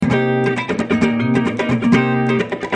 Free MP3 funk music guitars loops & sounds 3
guitar loop - funk 46